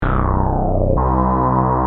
Sequencial Circuits - Prophet 600 46